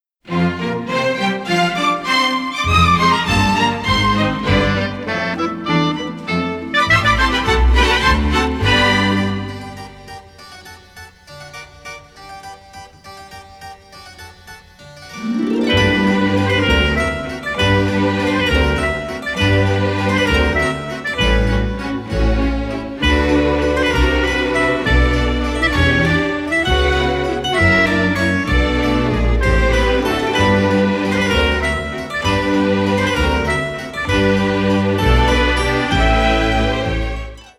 tense suspense music